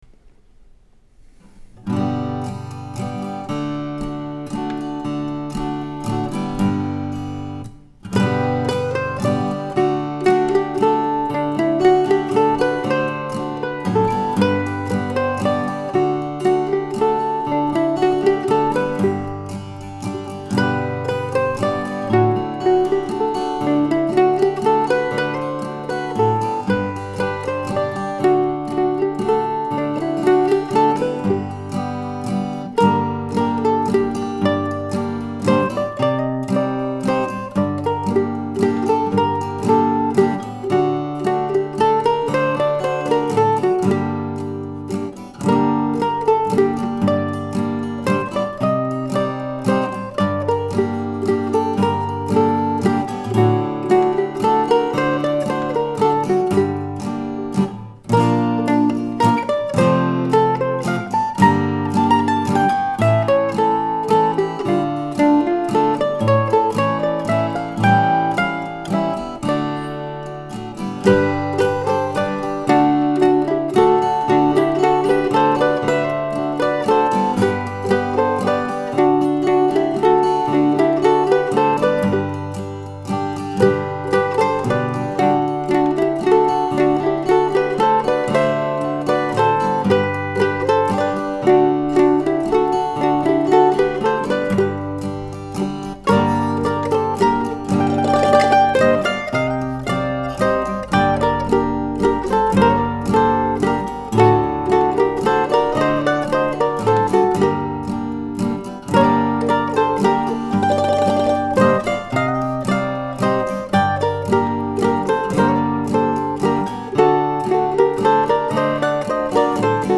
Here's a nice waltz from last November, along with another Deer Track composition from late December 2020.